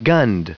Prononciation du mot gunned en anglais (fichier audio)
Prononciation du mot : gunned